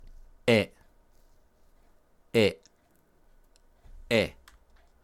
Esta vocal que se pronuncia como nuestra E, puede aparecer escrita de dos maneras diferentes (pero no cambia su pronunciación)
Pronunciación 에,애